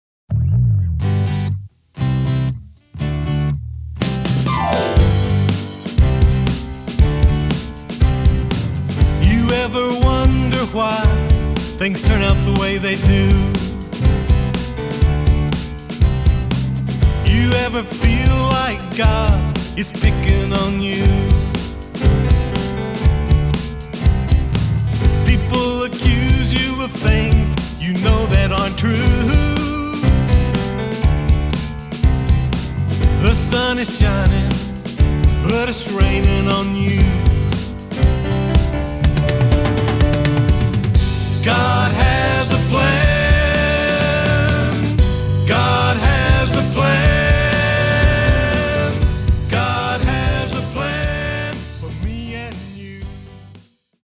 Autoharp, Lead & Harmony Vocals
Drums, Lead & Harmony Vocals
Keyboards, Bass Vocals